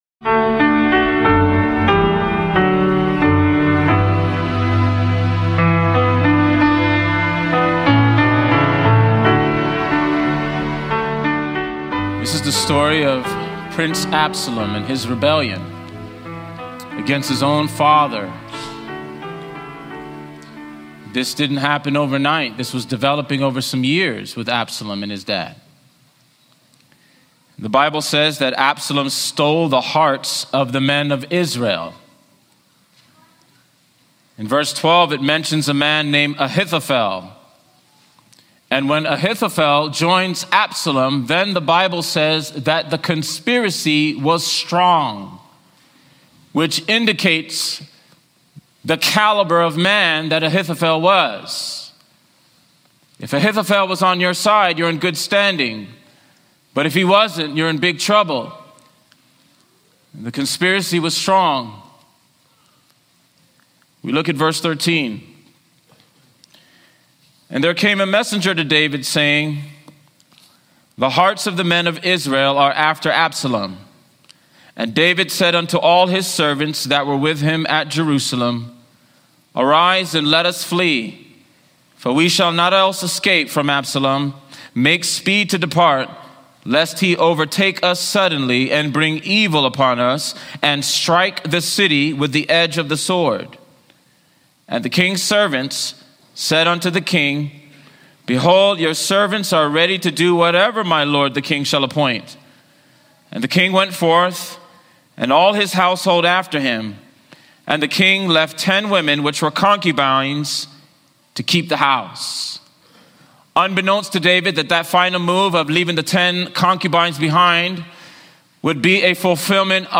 Absalom’s rebellion against David is more than a political struggle—it’s a story of unresolved pain, leadership, and divine sovereignty. This sermon explores the personal and spiritual lessons from David’s trials, showing how faith, repentance, and true loyalty shape our understanding of God’s greater plan.